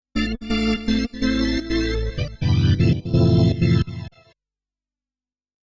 ORGAN022_VOCAL_125_A_SC3(L).wav